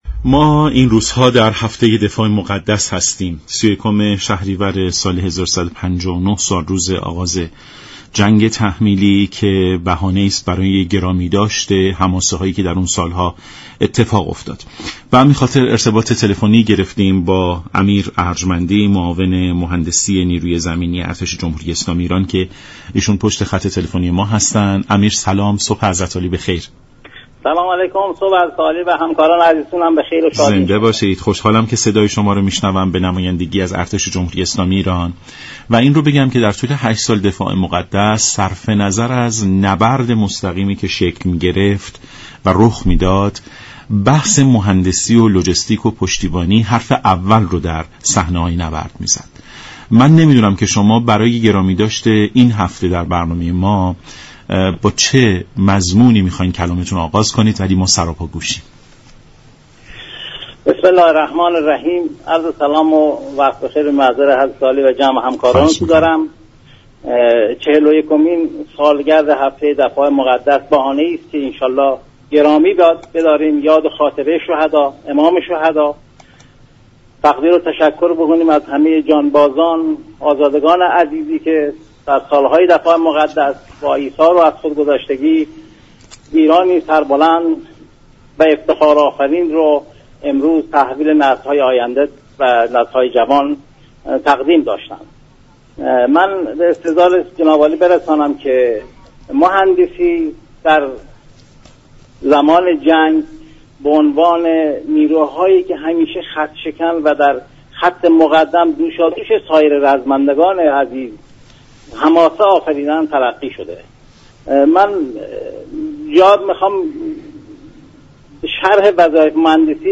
معاون مهندسی نیروی زمینی ارتش جمهوری اسلامی گفت:در عملیات فتح المبین، برای انتقال تجهیزات نظامی رزمندگان به خاك دشمن و خلق آن حماسه بزرگ، بالغ بر 11 پل بر رودخانه كرخه احداث شد.